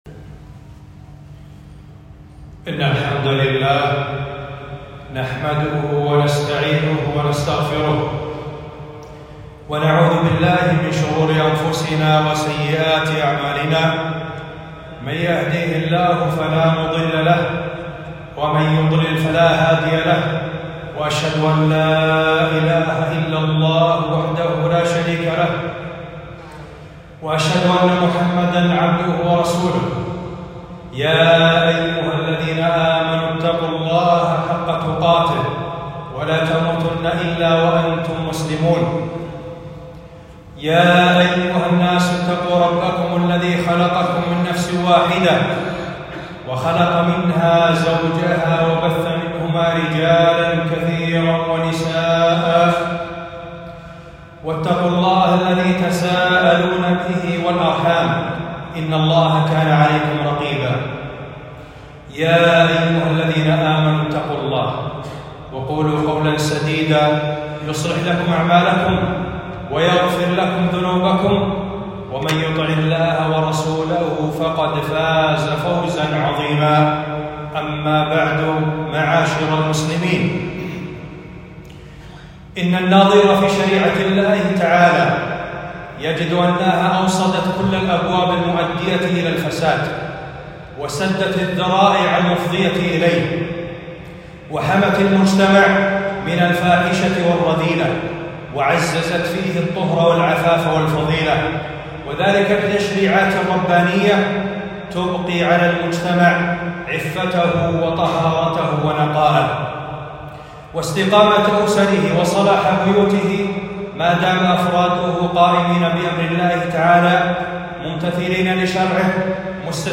خطبة - الاختلاط من أعظم أسباب الفساد